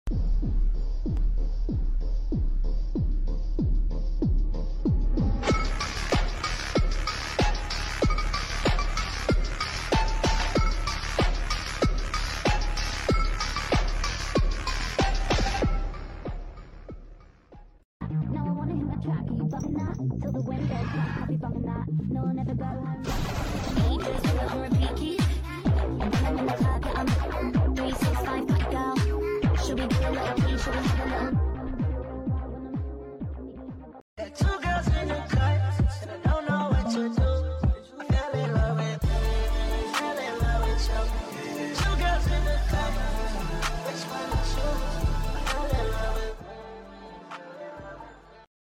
tiktok funny sound hahaha